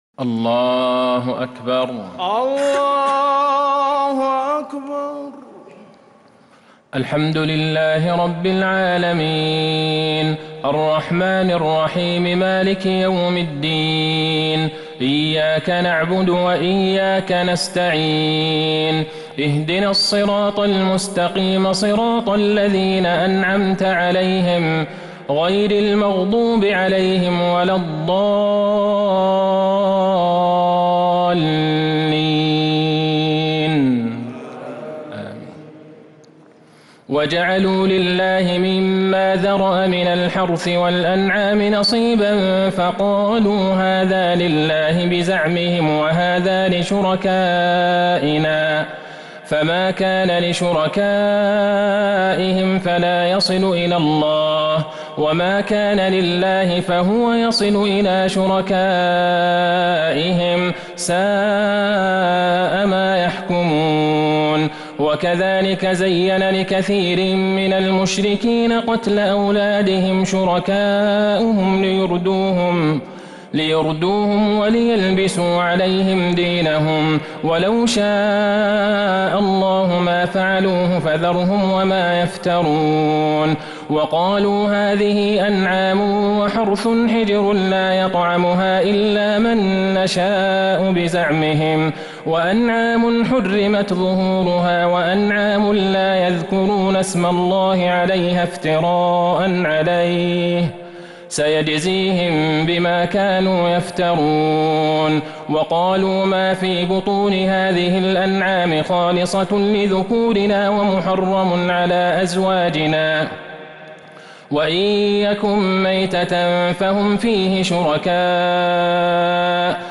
صلاة التراويح | ليلة ١٠ رمضان ١٤٤٢هـ | أواخر الأنعام 136-آخرها | Taraweeh 10st night Ramadan 1442H > تراويح الحرم النبوي عام 1442 🕌 > التراويح - تلاوات الحرمين